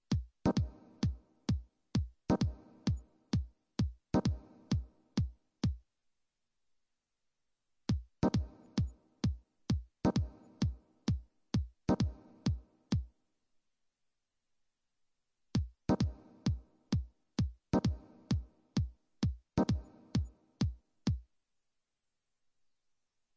描述：打击乐器中的打击乐器节拍和打击乐从yamaha键盘录制到老板数字机器上然后以大胆的方式实现